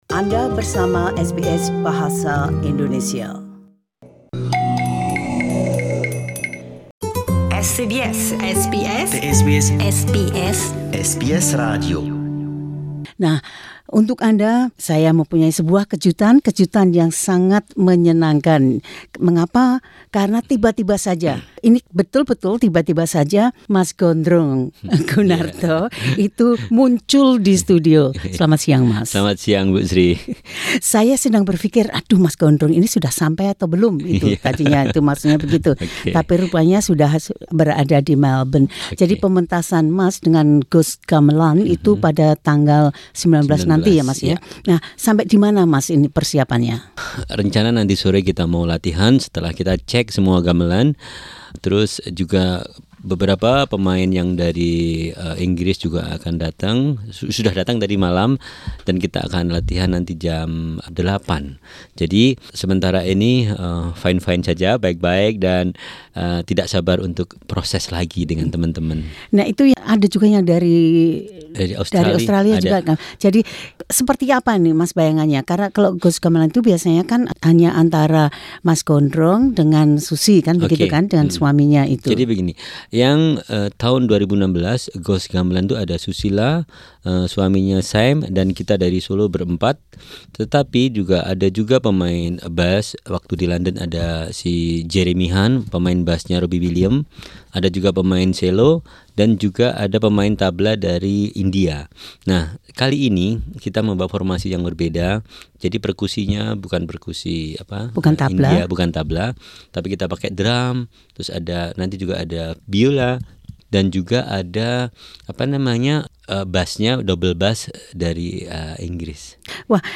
having an interview in the SBS studio in Melbourne